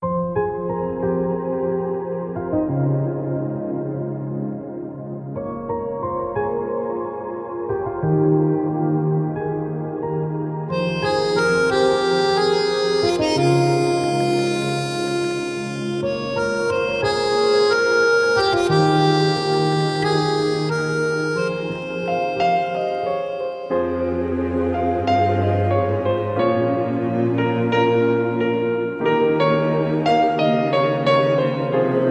instrumental cover
Category: Instrumental Ringtones